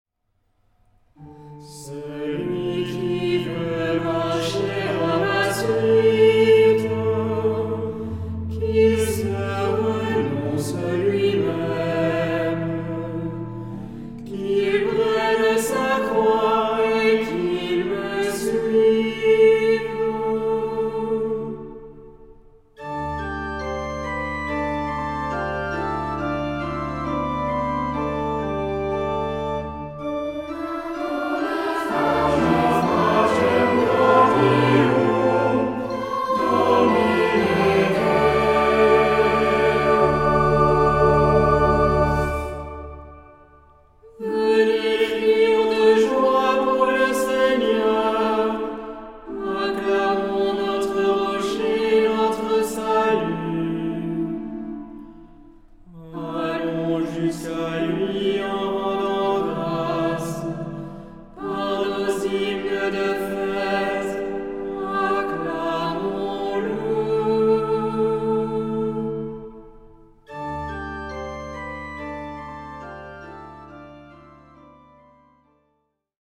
SATB (4 voces Coro mixto) ; Partitura general.
Salmodia.